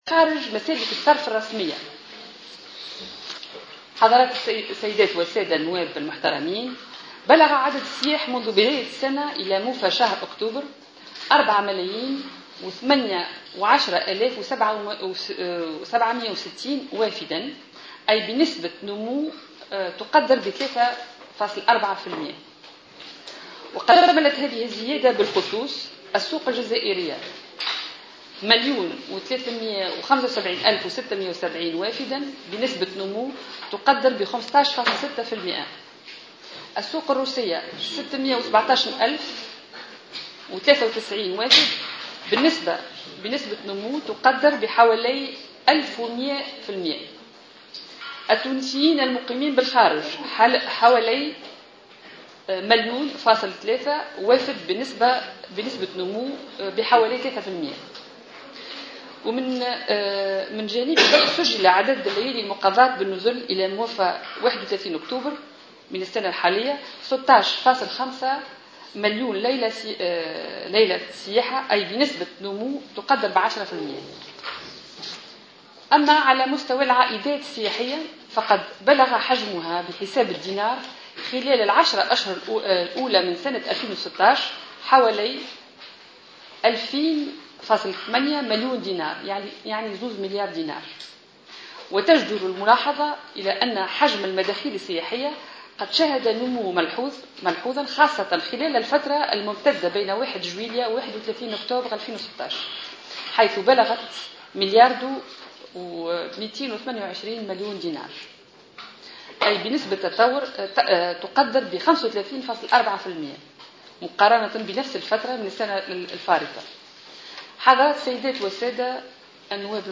لاحظت وزيرة السياحة سلمى اللومي اليوم الثلاثاء خلال جلسة مع لجنة الفلاحة والأمن الغذائي والتجارة والخدمات، تراجعا في الميزانية المخصصة للقطاع السياحي بعنوان ميزانية الدولة لـ 2017.